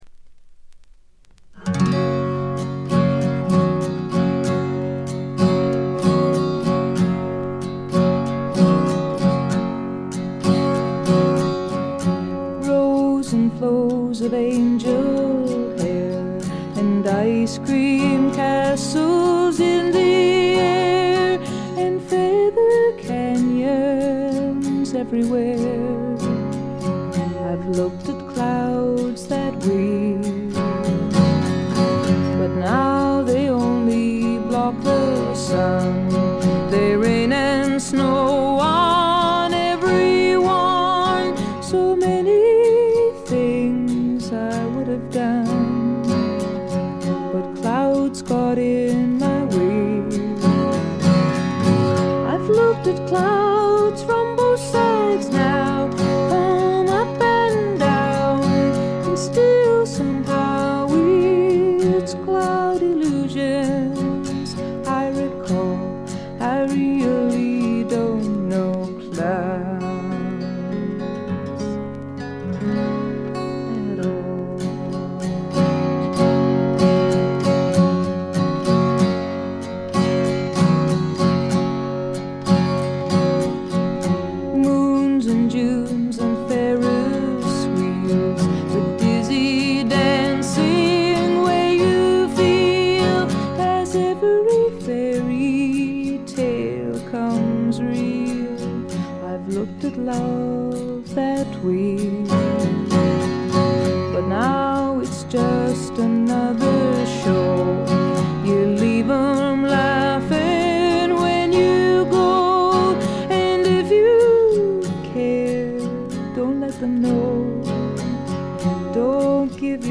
ところどころで軽微なバックグラウンドノイズに気づく程度。
透明感のあるみずみずしさが初期の最大の魅力です。
女性フォーク／シンガーソングライター・ファンなら避けては通れない基本盤でもあります。
試聴曲は現品からの取り込み音源です。
guitar, keyboards, vocals